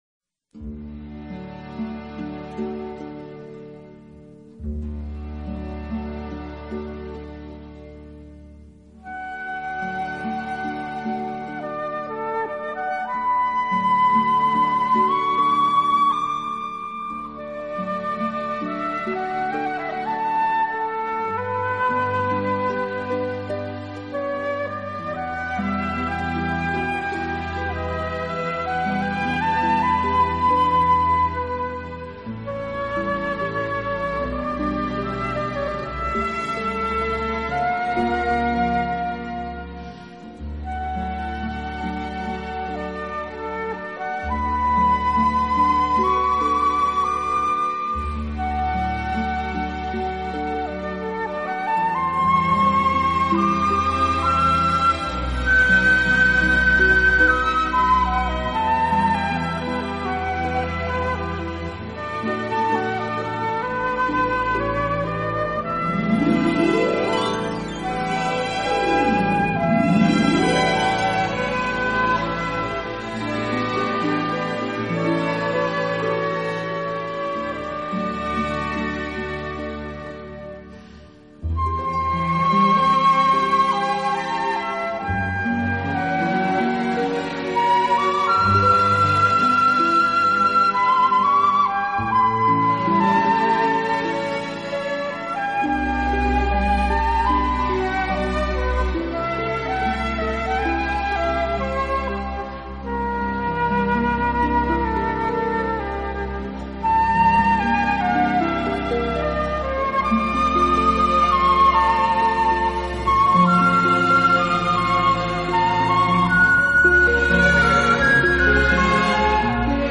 音乐类型：Classical
长笛的音色清冷高雅，宛如一位冰雪美人，低调中透着活泼明丽，圆润而绚丽，
经典长笛名曲，专业数码录音，不可多得的精选。